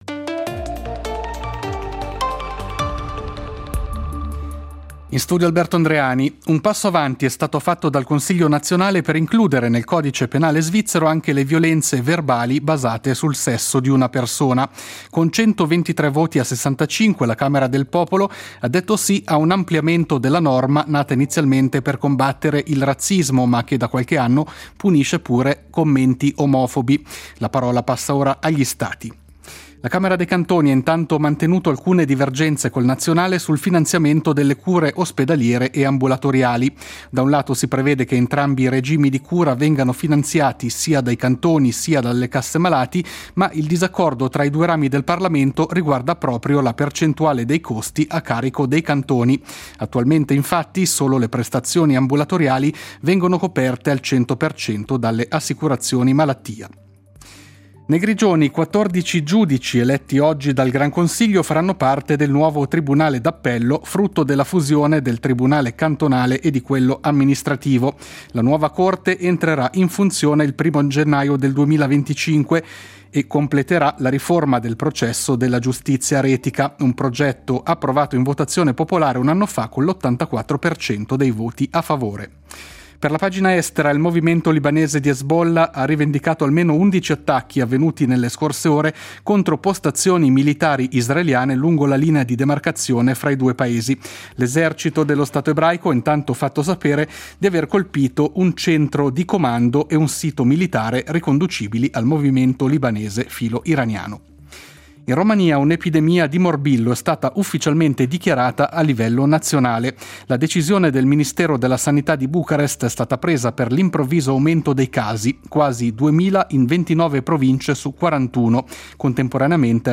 Notiziario delle 21:00 del 06.12.2023